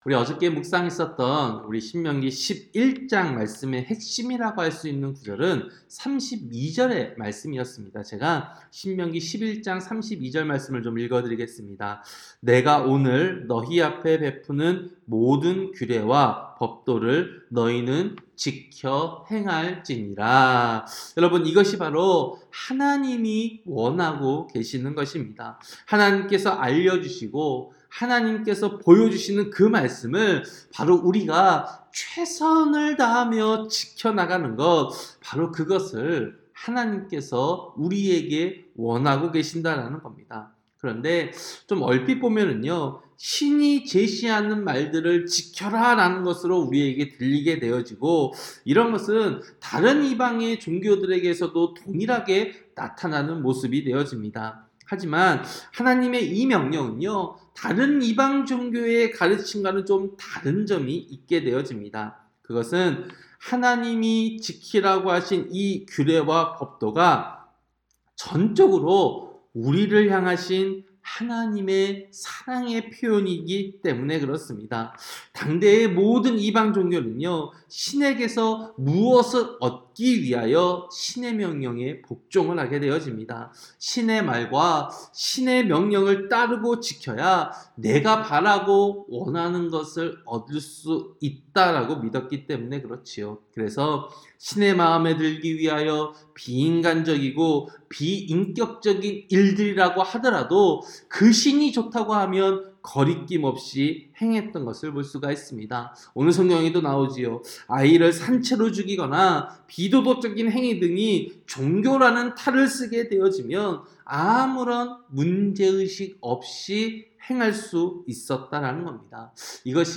새벽기도-신명기 12장